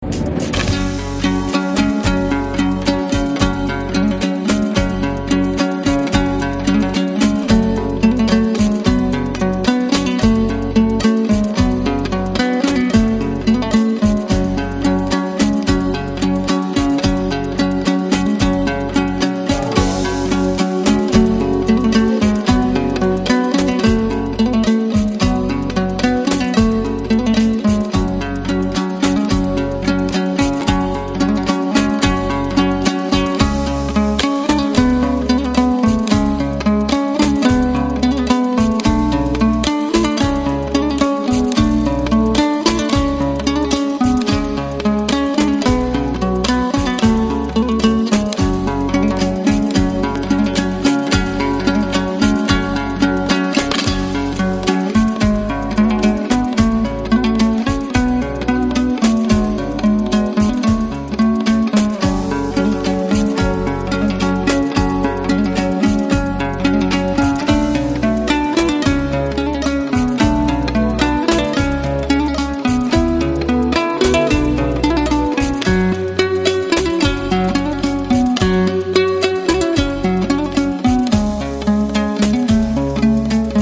• Качество: 80, Stereo
гитара
без слов
инструментальные
Народные
этнические
домбра